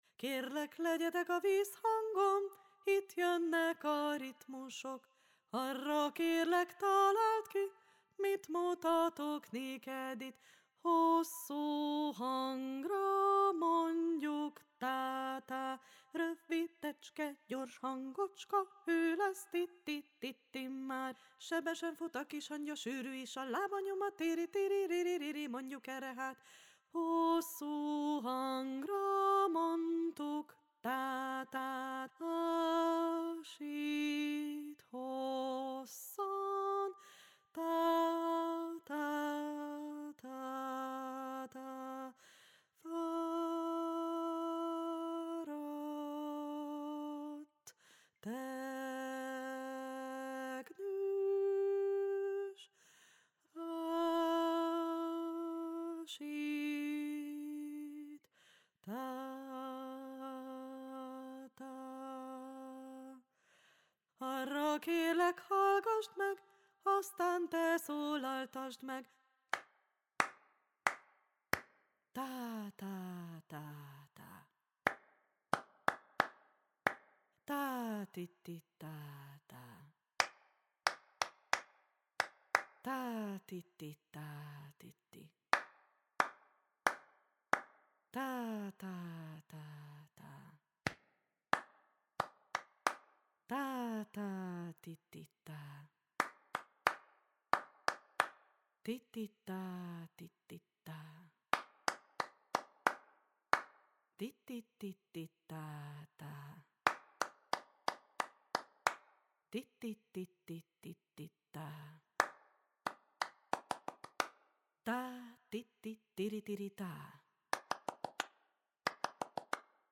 RITMUSVILÁG mondóka és játék _ Köszöntő, alapok és karbantartó játék, Mindenkinek